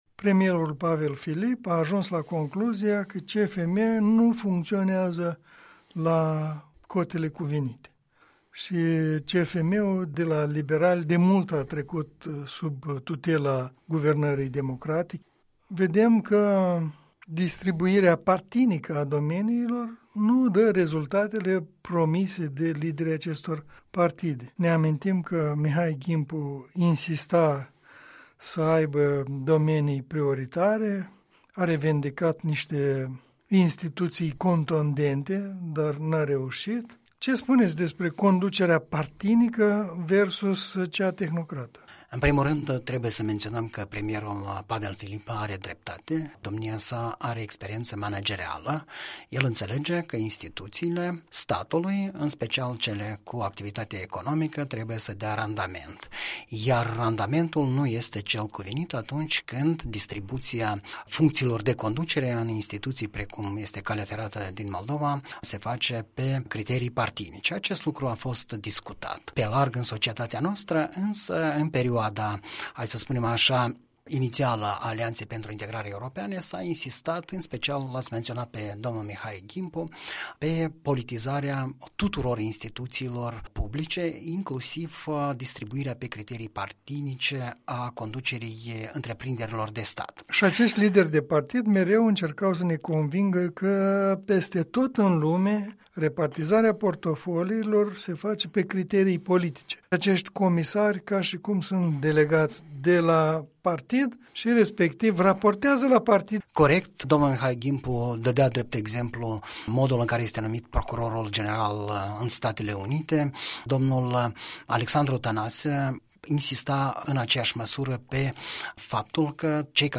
Punct de vedere săptămânal, în dialog la Europa Liberă